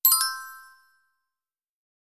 Звон галочки для монтажа